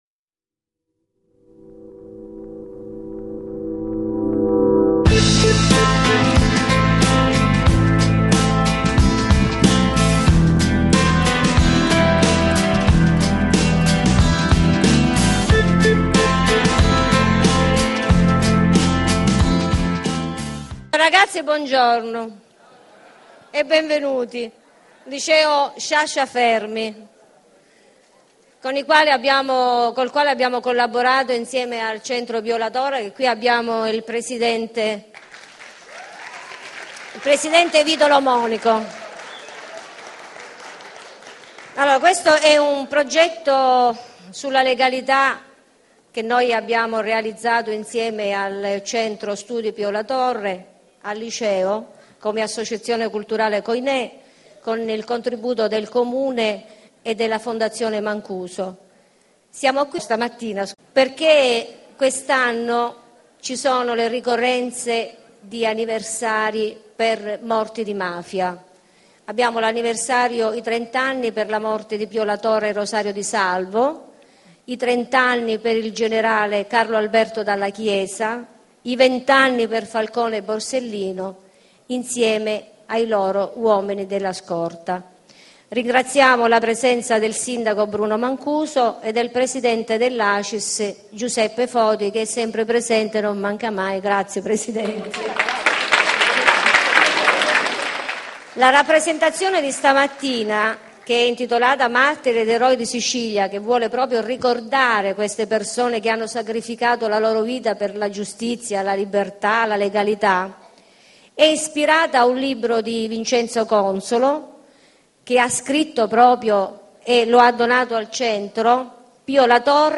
Cine Teatro Aurora Sant'Agata di Militello (Me)
Rappresentazione